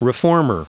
Prononciation du mot reformer en anglais (fichier audio)
Prononciation du mot : reformer